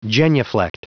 Prononciation du mot genuflect en anglais (fichier audio)
Prononciation du mot : genuflect